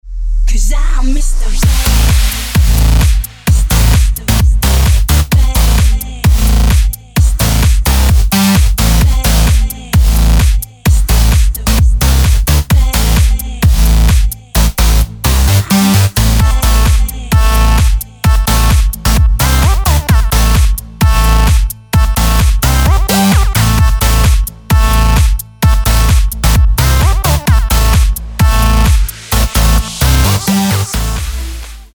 В этом разделе можно бесплатно скачать клубные рингтоны.